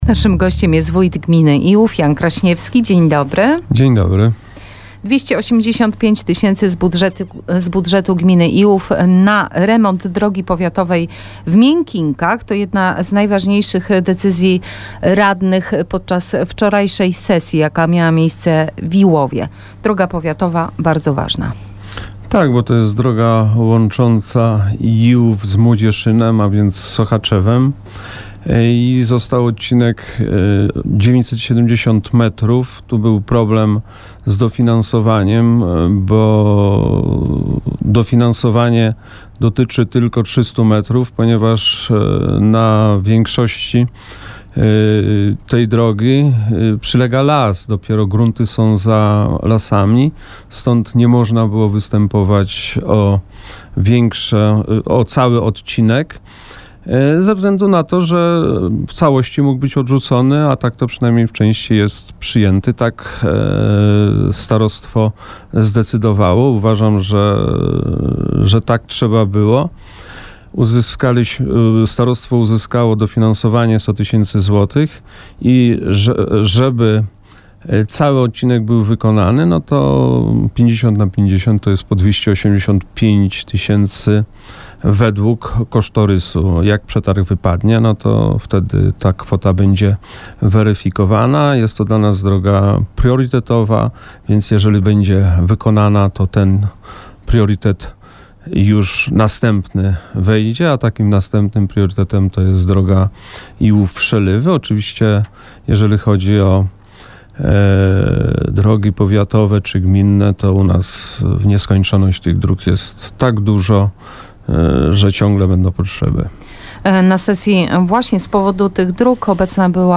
Wywiad z Wójtem Janem Kraśniewskim w Radio Sochaczew - Najnowsze - Gmina Iłów